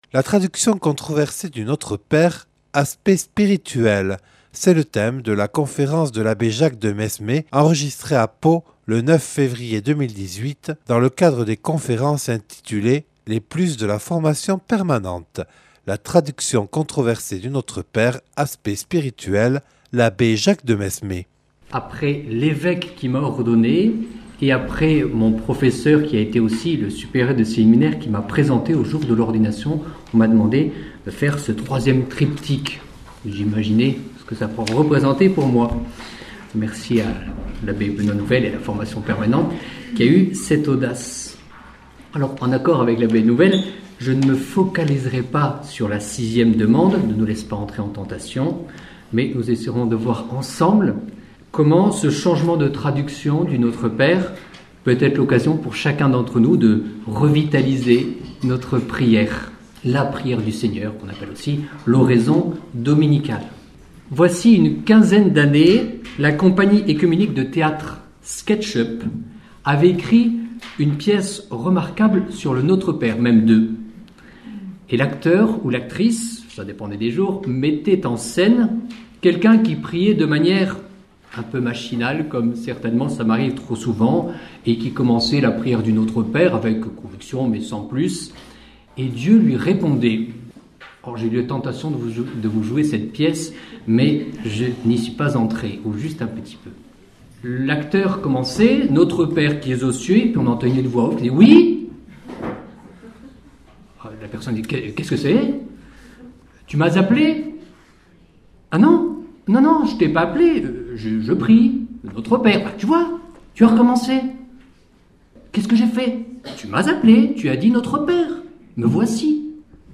Conférence
(Enregistrée le 09/03/2018 lors d’une soirée proposée par le Service diocésain de la Formation permanente en Béarn).